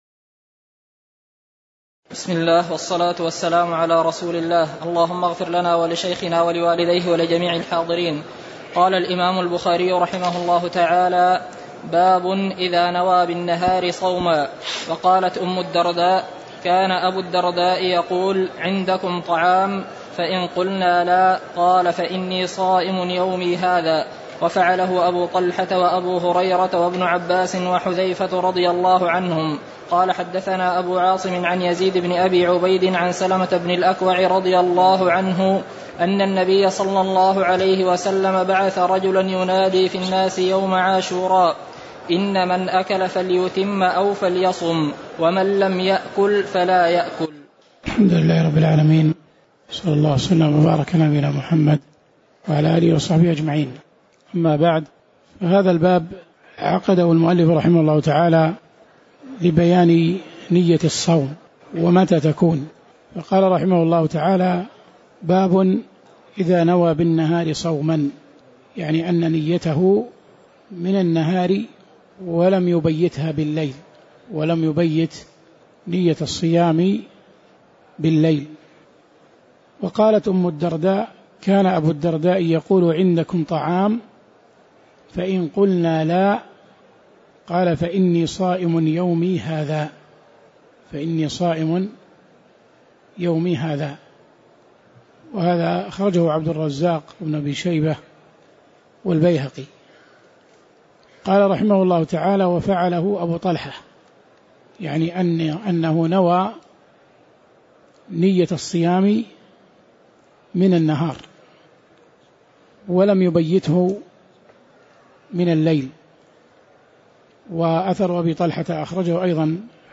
تاريخ النشر ٦ رمضان ١٤٣٨ هـ المكان: المسجد النبوي الشيخ